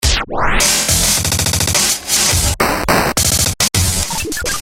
描述：在最后几个节拍上，有一点压缩和一个漂亮的异形效果的混杂循环。
Tag: 105 bpm Glitch Loops Drum Loops 787.67 KB wav Key : Unknown